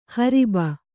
♪ hariba